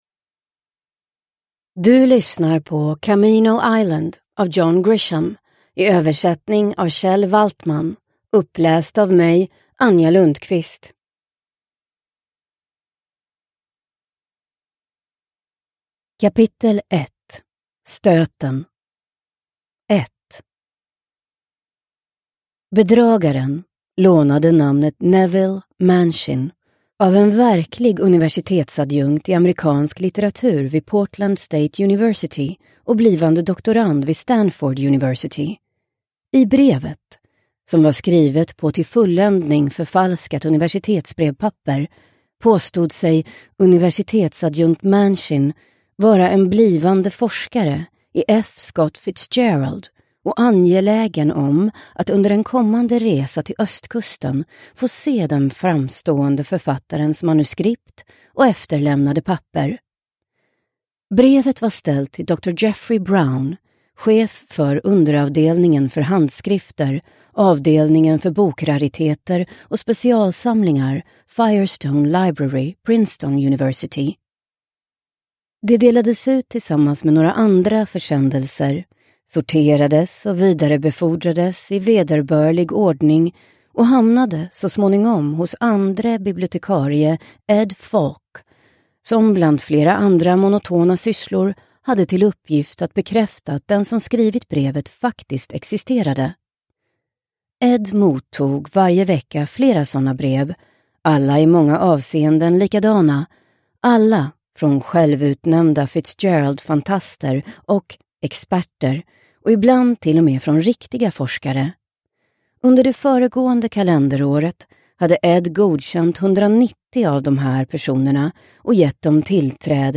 Camino Island – Ljudbok – Laddas ner